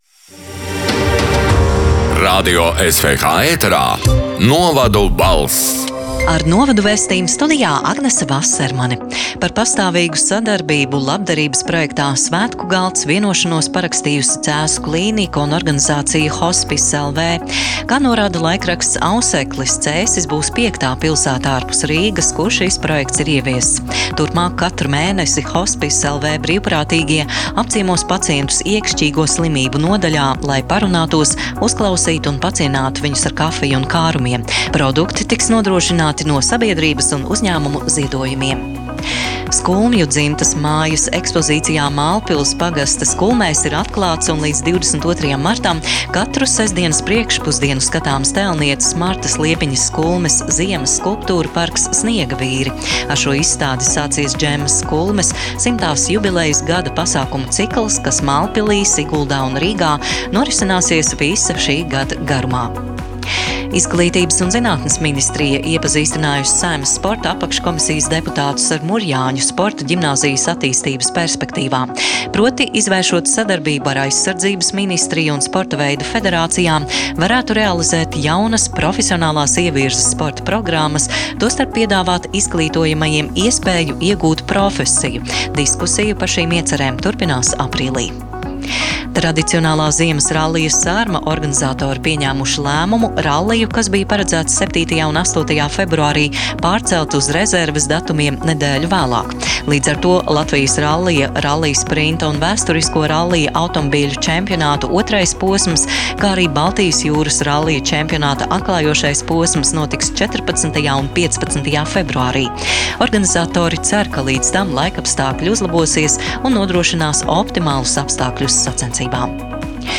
“Novadu balss” 5. februāra ziņu raidījuma ieraksts: